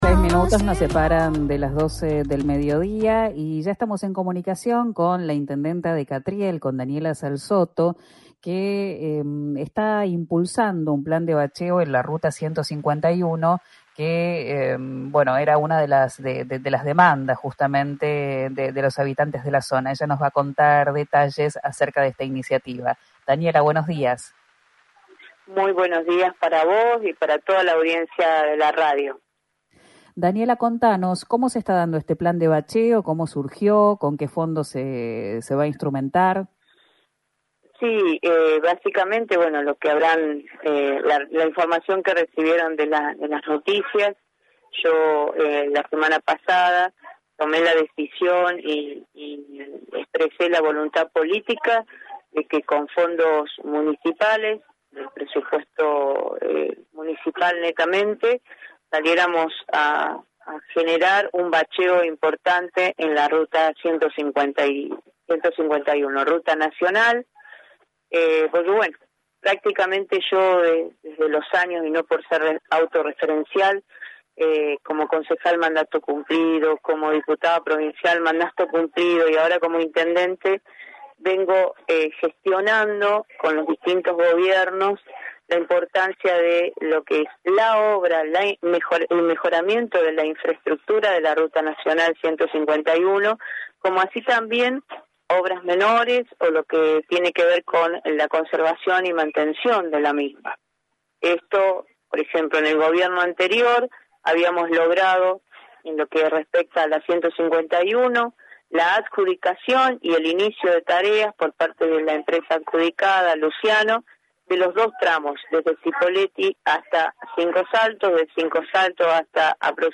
Escuchá a Daniela Salzotto, intendenta de Catriel en RÍO NEGRO RADIO: